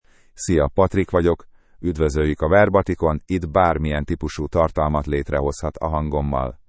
PatrickMale Hungarian AI voice
Patrick is a male AI voice for Hungarian (Hungary).
Voice: PatrickGender: MaleLanguage: Hungarian (Hungary)ID: patrick-hu-hu
Voice sample
Listen to Patrick's male Hungarian voice.
Patrick delivers clear pronunciation with authentic Hungary Hungarian intonation, making your content sound professionally produced.